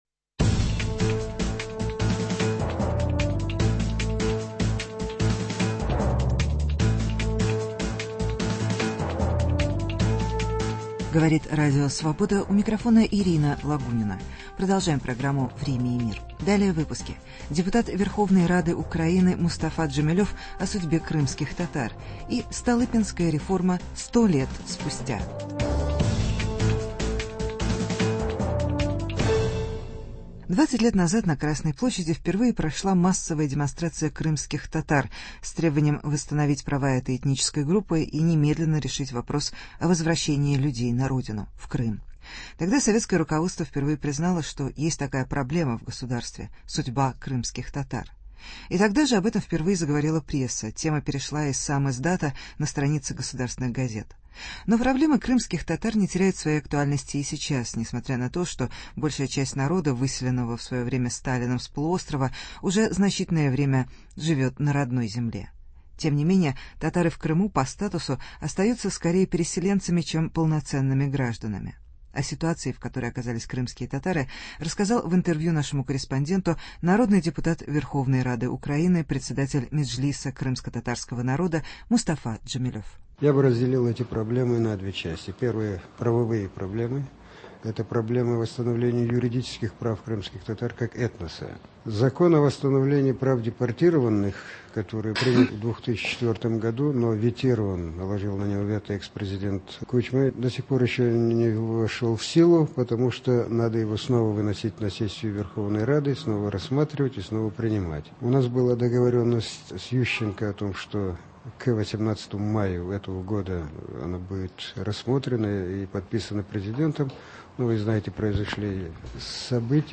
Проблемы крымских татар. Интервью с Мустафой Джемилевым.